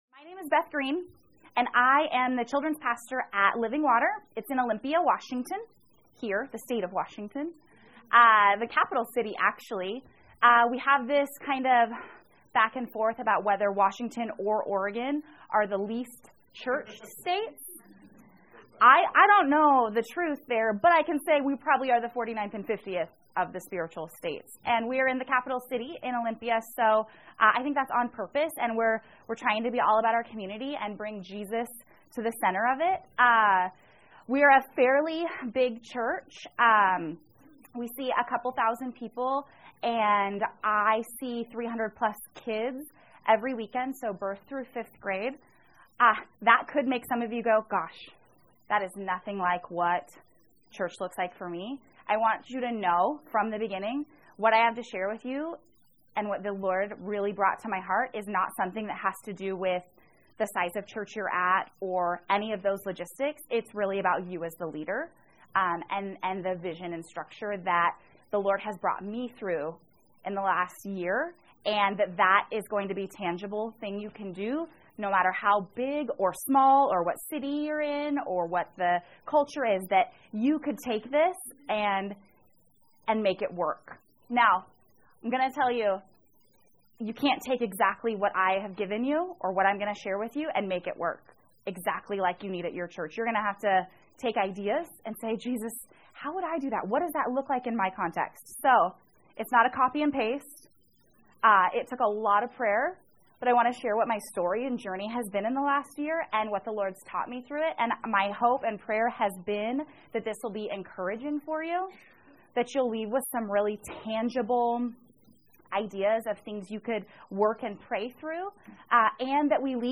Workshop: How Jesus turned our children’s ministry upside down